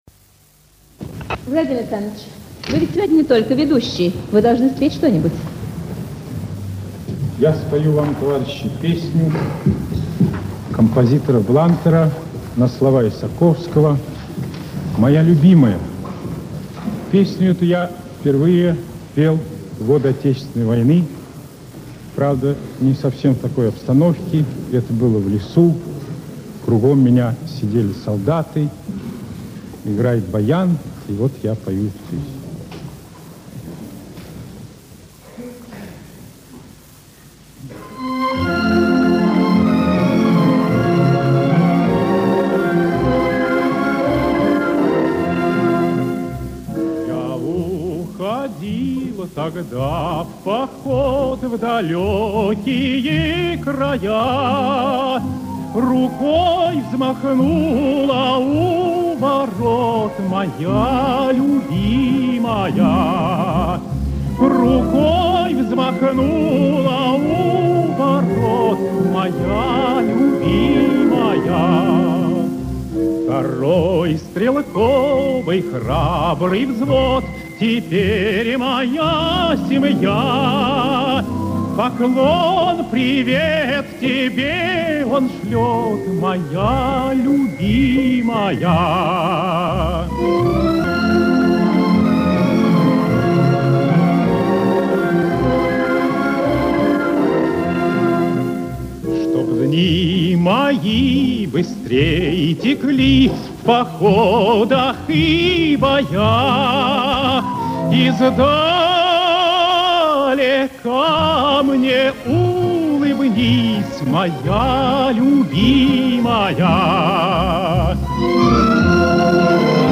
Здесь улучшено звучание музыкальной части.